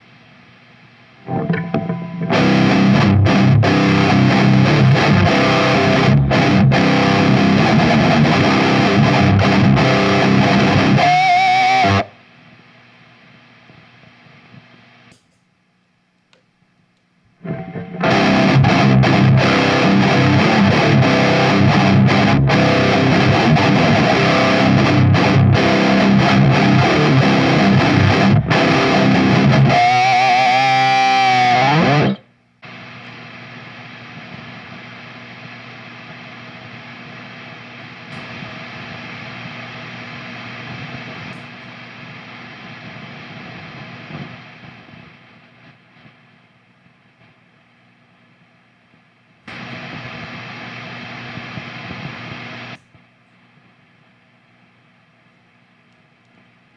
PEAVEY 5150にての僕的な使い方です。
PEAVEY 5150はとりあえず歪むアンプなのでノイズもそれなりに。
ゲインの高いアンプのシーって音を消すもんだと考えてます。
実際に使うとノイズが消えていくのが分かります。
ゲイン７でローインプットでEMG 81です。